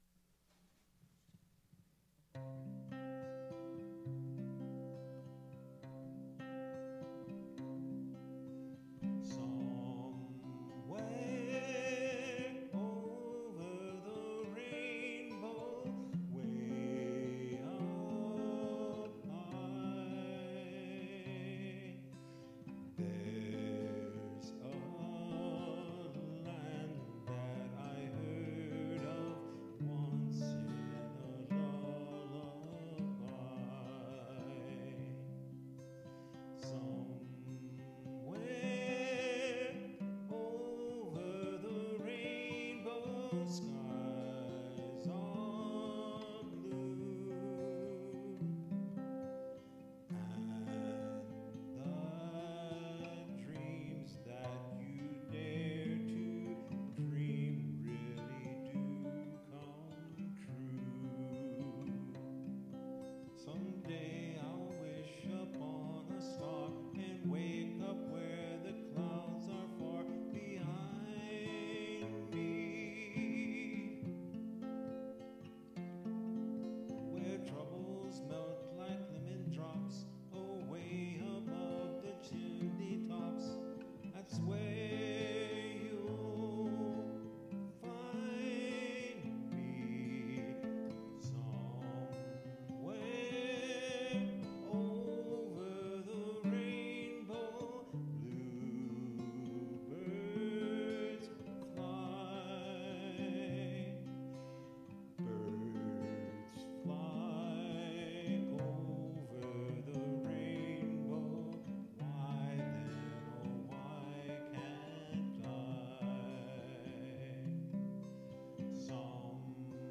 MP3 Copy of Studio Recording
Guitar
Vocal